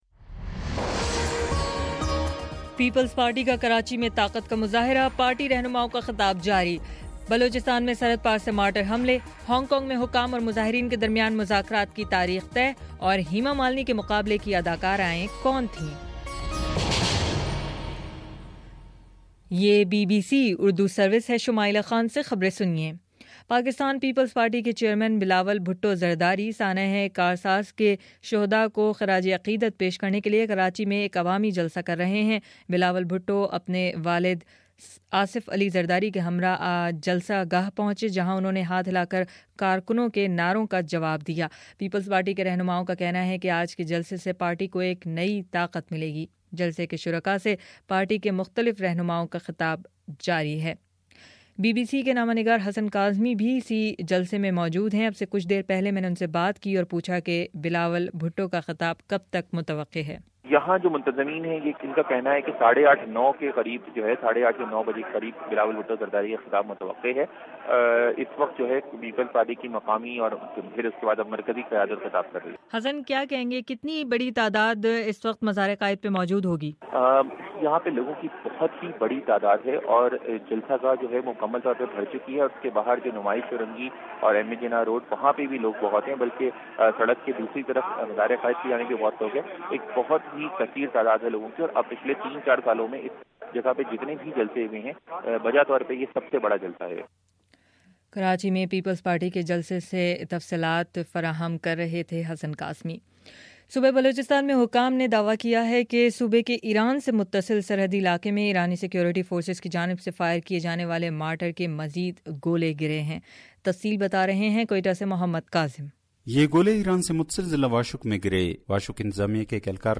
اکتوبر18: شام سات بجے کا نیوز بُلیٹن
دس منٹ کا نیوز بُلیٹن روزانہ پاکستانی وقت کے مطابق صبح 9 بجے، شام 6 بجے اور پھر 7 بجے۔